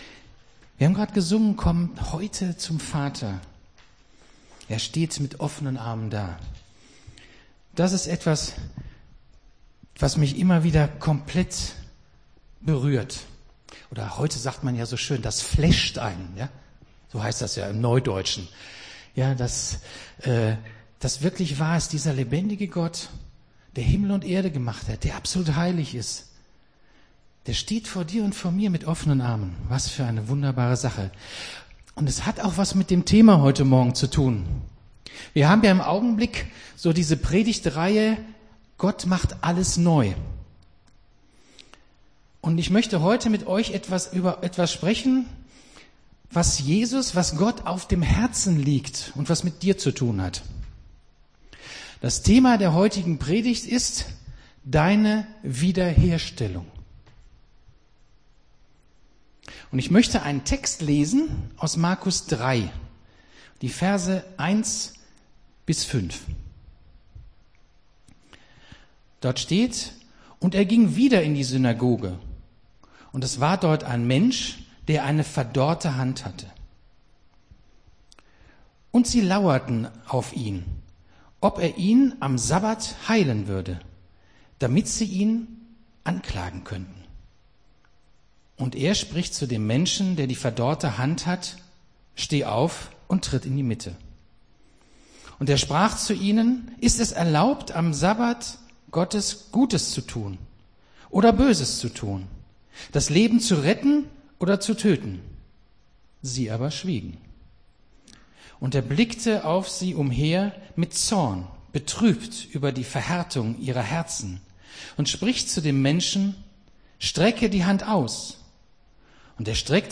Gottesdienst 06.02.22 - FCG Hagen